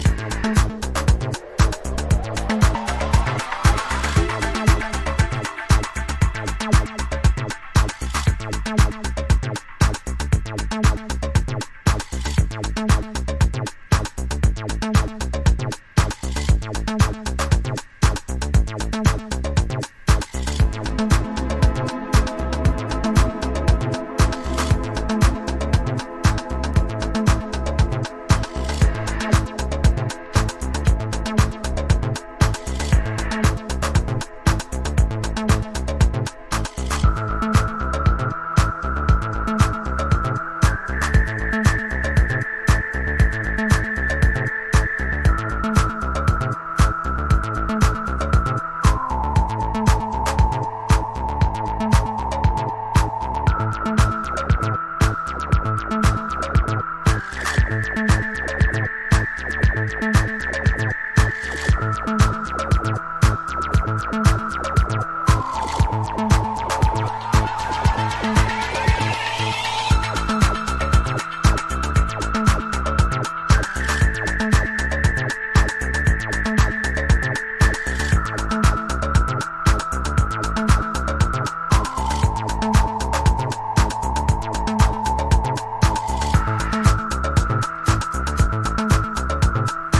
ELECTRO HOUSE / TECH HOUSE